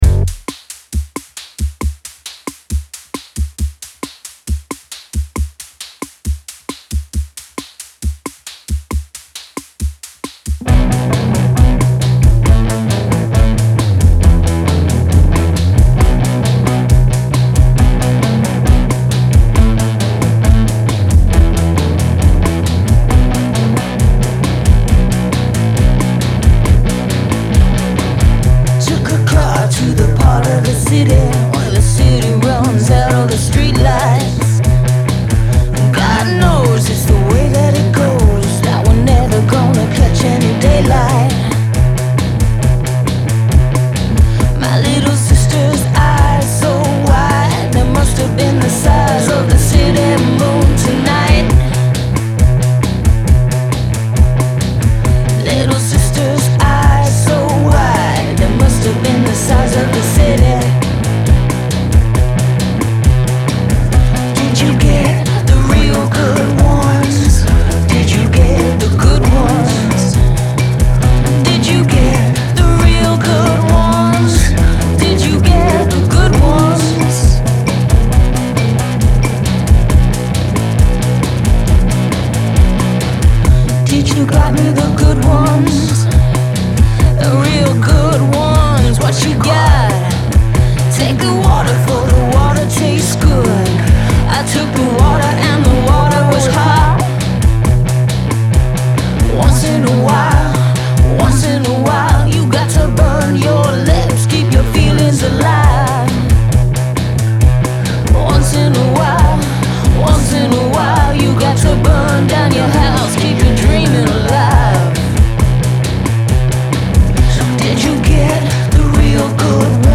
Genre : Pop, Rock, Alternatif et Indé